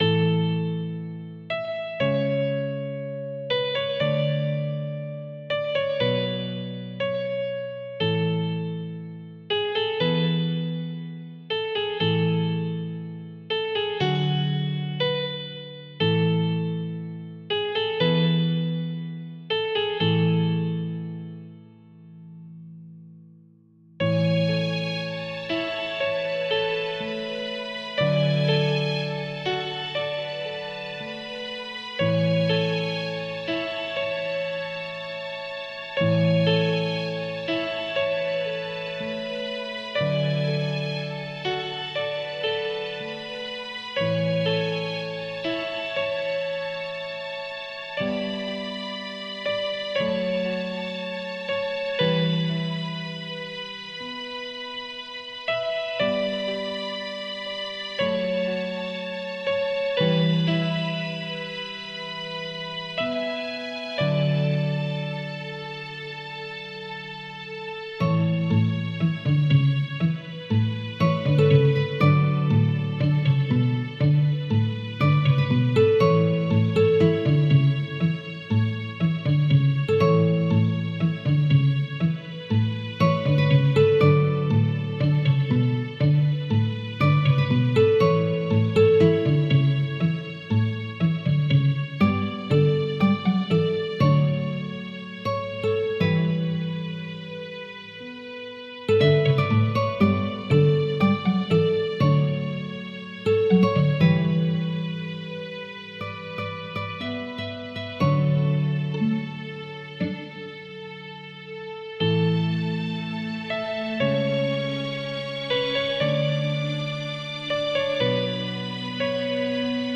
Нежная романтическая музыка без слов